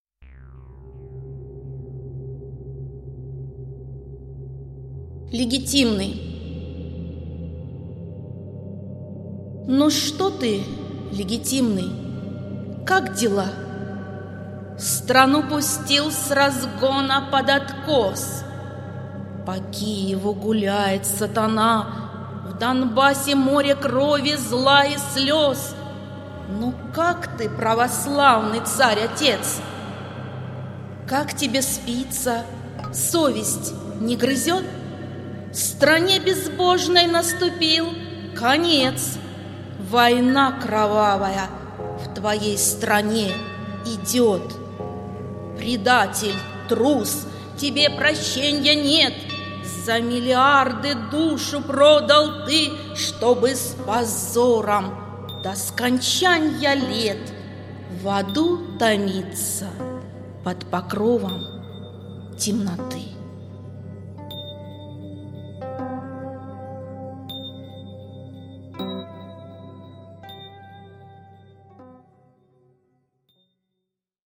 Читает Автор